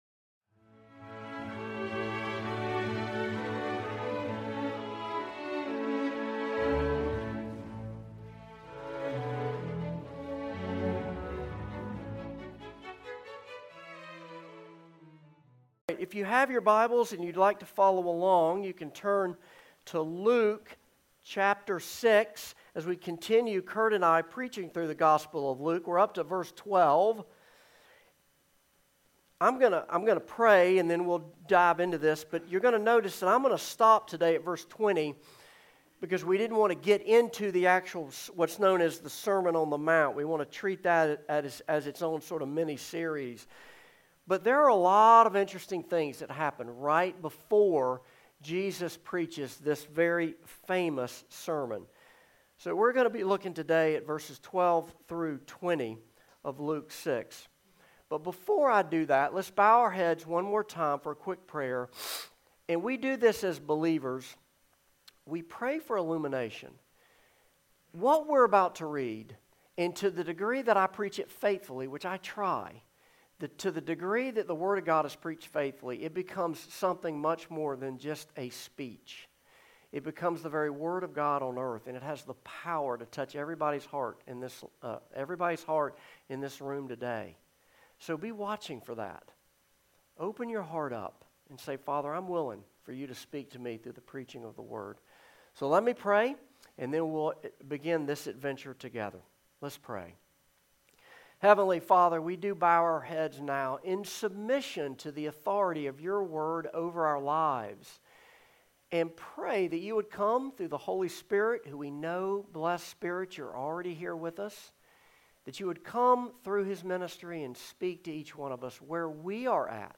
Service Type: Morning Service
Sermon-Intro_Joined.mp3